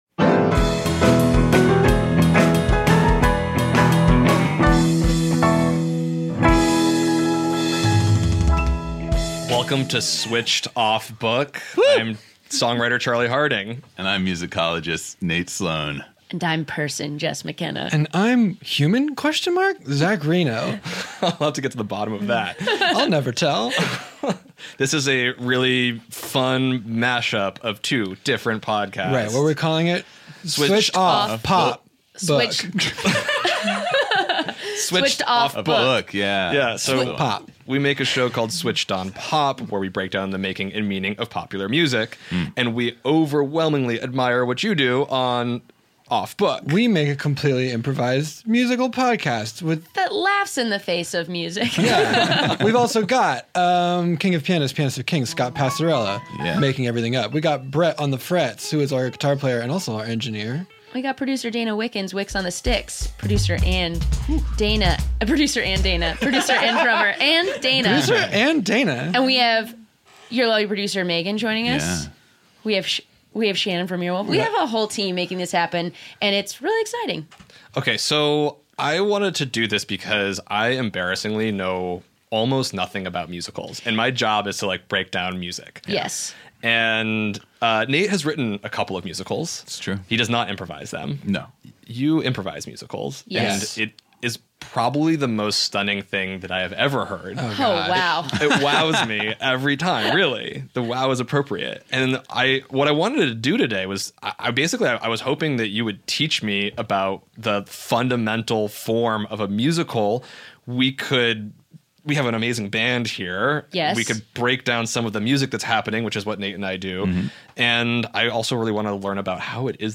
Stay tuned for deep thoughts about what separates pop music from musicals, wild speculation about the origin of the word “vamp,” and an ENTIRE FREAKING MUSICAL COMPOSED FROM SCRATCH that will make you laugh your face off.